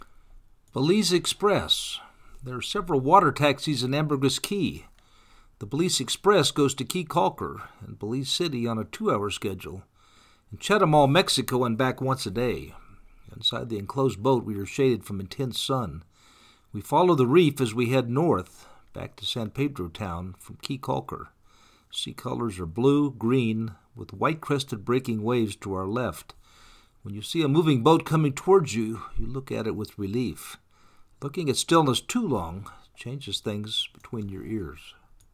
Belize Express Water taxi
Inside the enclosed boat we are shaded from intense sun. We follow the reef as we head north back to San Pedro Town from Caye Caulker. Sea colors are blue, green, with white crested breaking waves to our left.